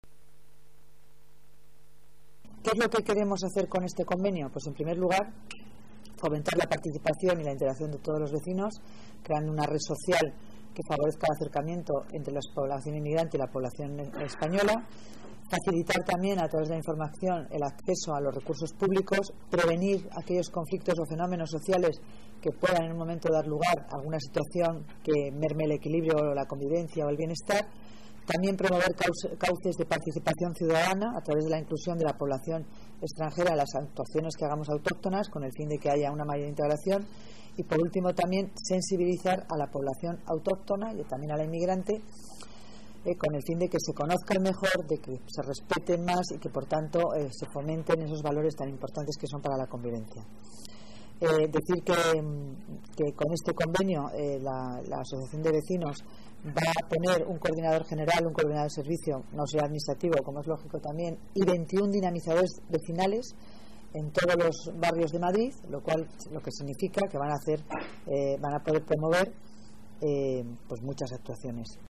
Nueva ventana:Declaraciones de Dancausa sobre el convenio con la FRAVM